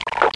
DRINK.mp3